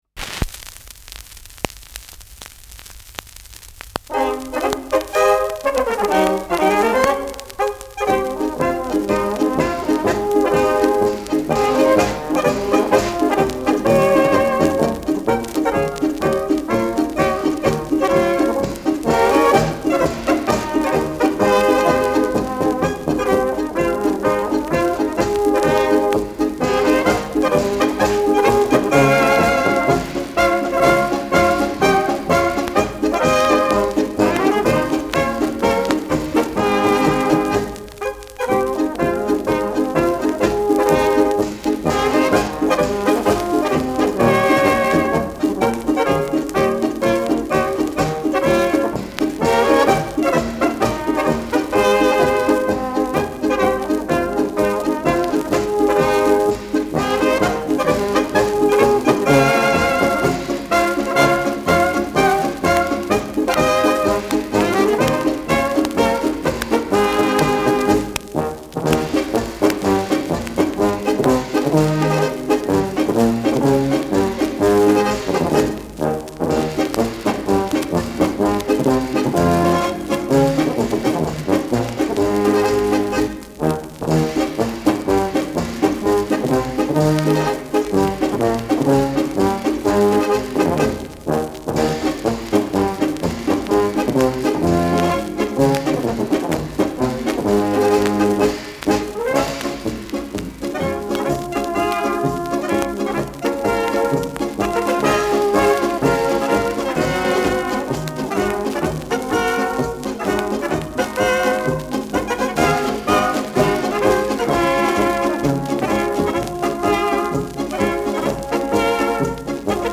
Marsch
Schellackplatte
Abgespielt : Durchgehend leichtes bis stärkeres Knacken
Folkloristisches Ensemble* FVS-00015